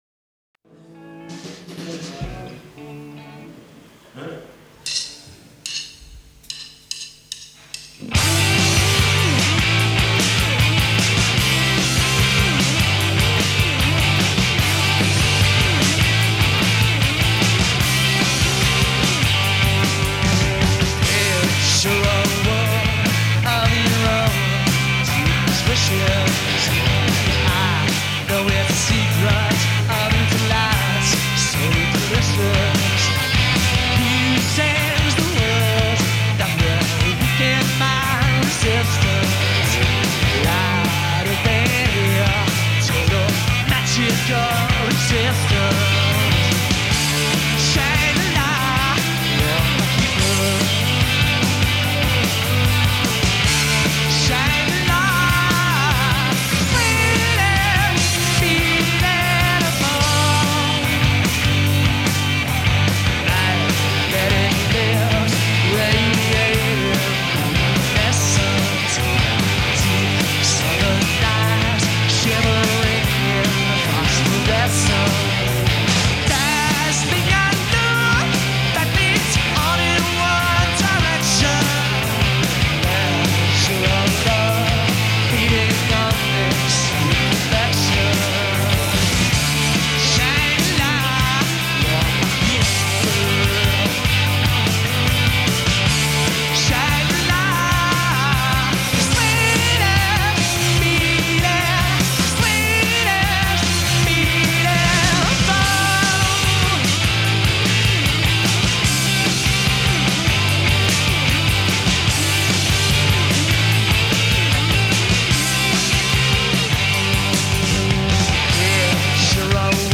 enregistrée le 08/06/1993  au Studio 105